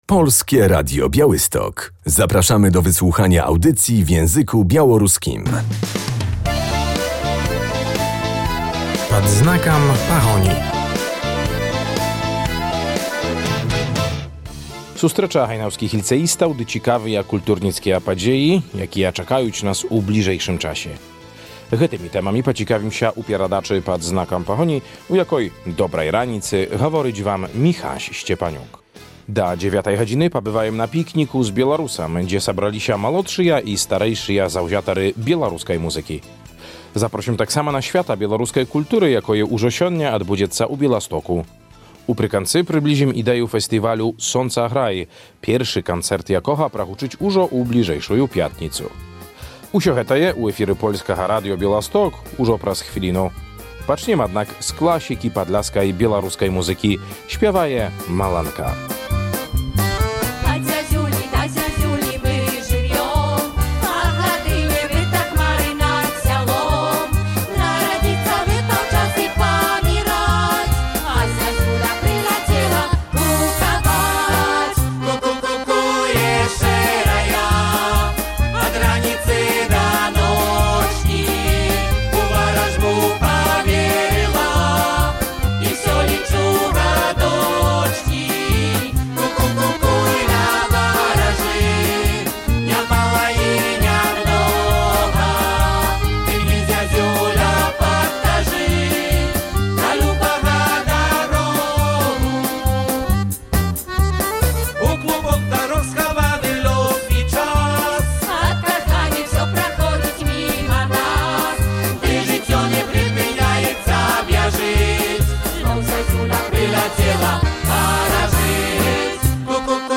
W audycji Pad znakam Pahoni będziemy w Hajnówce na 10. Pikniku z Białorusem. Wystąpili na nim uczniowie II LO, absolwenci, a także najmłodsi wykonawcy z hajnowskich przedszkoli.